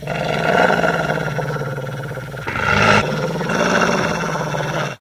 bdog_groan_1.ogg